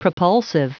Prononciation du mot propulsive en anglais (fichier audio)
Prononciation du mot : propulsive